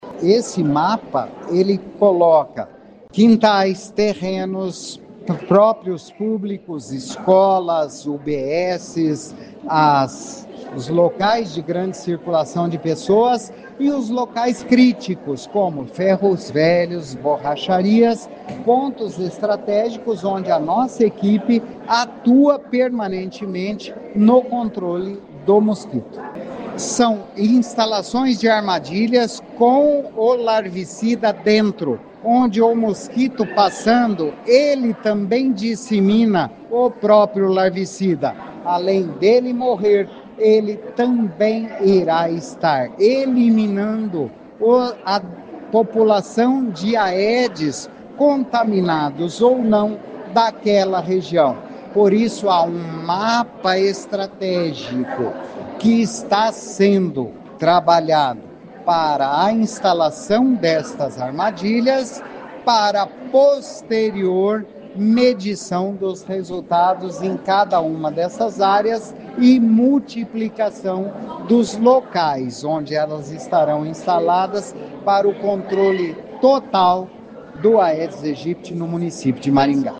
O secretário Antônio Carlos Nardi diz que um mapa com todos os locais guiará o trabalho dos agentes de endemia.